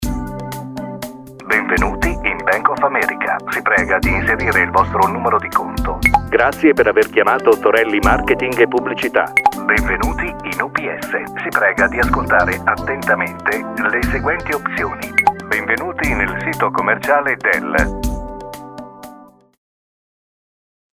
Sprechprobe: Sonstiges (Muttersprache):
Serious istitutional for narrations and documentaries.
Velvety smooth, playful, strong, elegant.Expressive voice that is able to convey many moods.